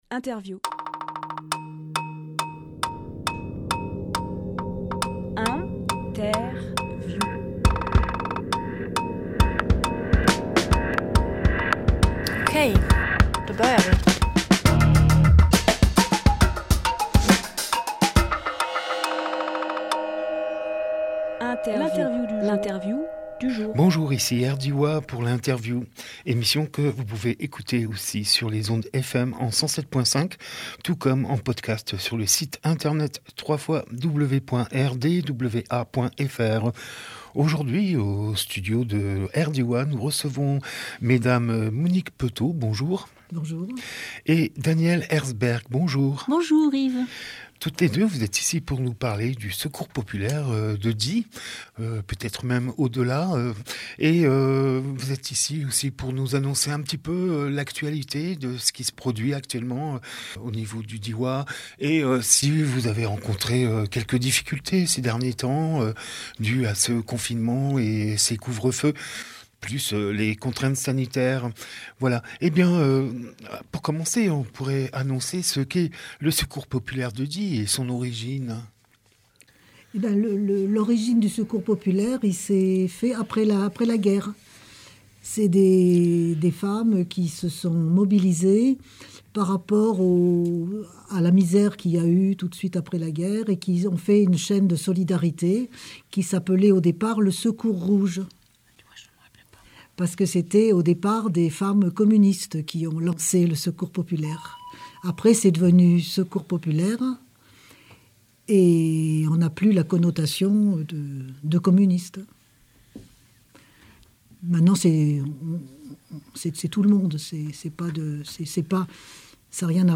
Emission - Interview Le Secours populaire à Die Publié le 1 avril 2021 Partager sur…
22.03.21 Lieu : Studio RDWA Durée